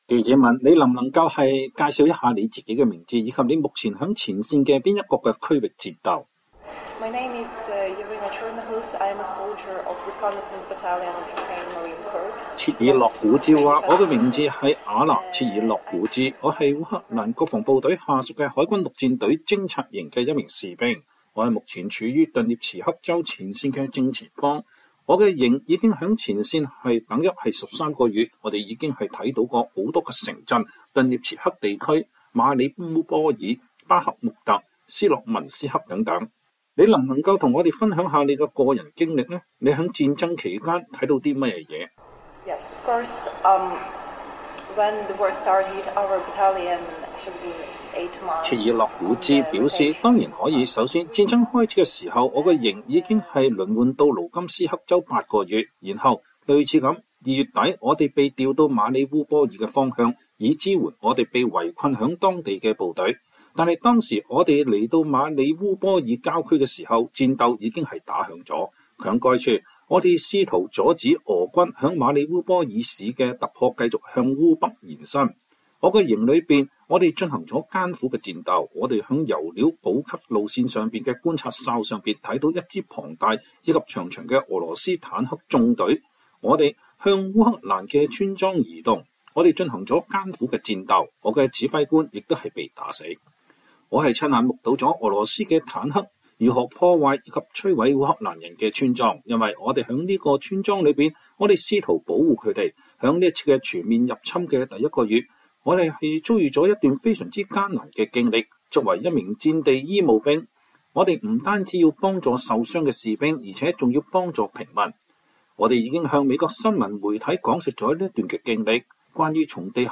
VOA專訪:烏克蘭女兵談前線苦戰經歷有了新火砲不怕俄軍動員